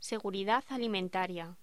Locución: Seguridad alimentaria
voz
Sonidos: Voz humana